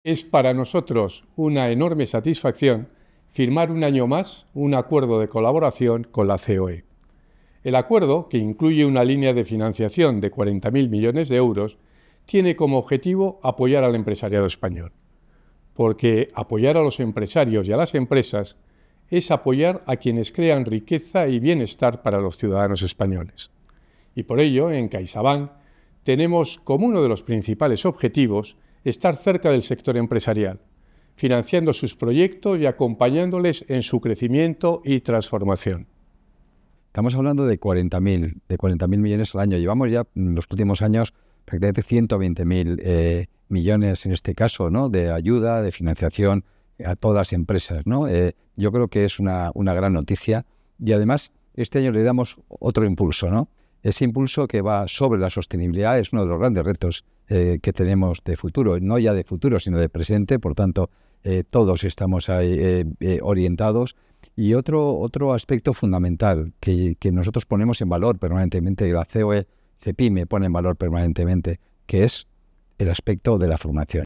Audio del presidente de CaixaBank, José Ignacio Goirigolzarri, y del presidente de la CEOE, Antonio Garamendi